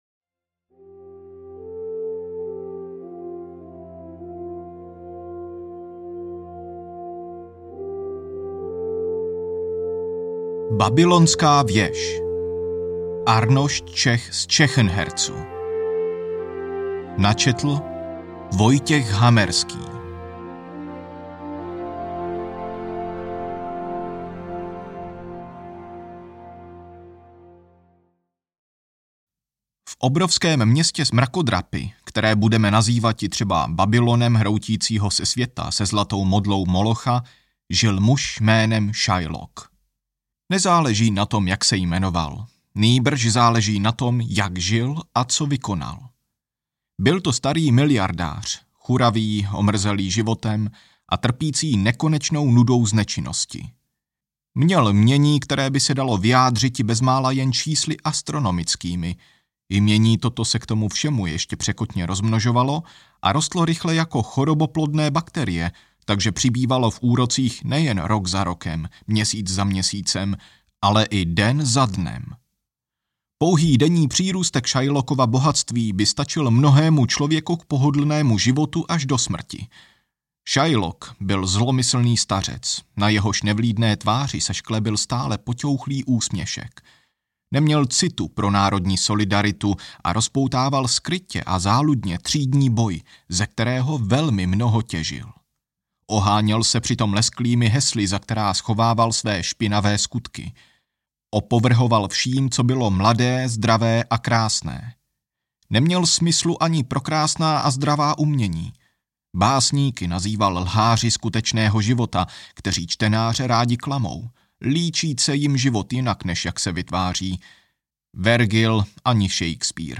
Babylonská věž audiokniha
Ukázka z knihy